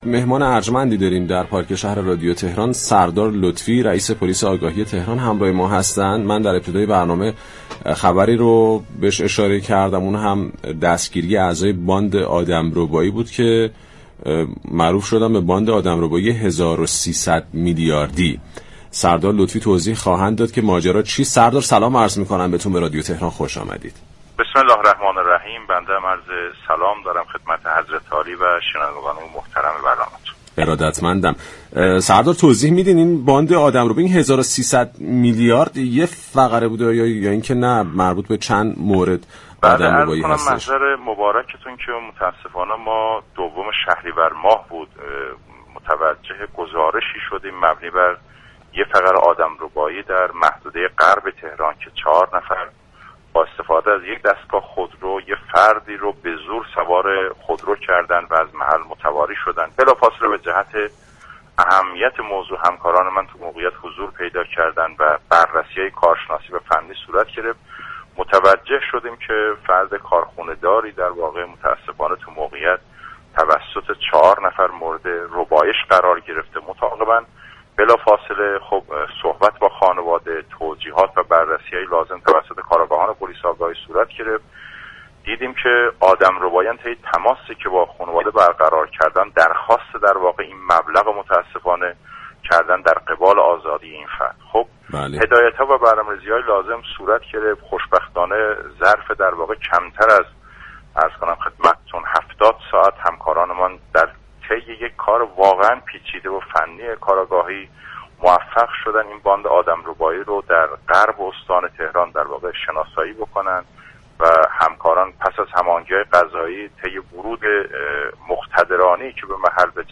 سردار علیرضا لطفی رئیس پلیس آگاهی تهران در گفتگو با برنامه پارك شهر رادیو تهران گفت: تمامی اعضای باند كلاهبرداری فروش خودروهای لیزینگی دستگیر شده اند.